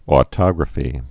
(ô-tŏgrə-fē)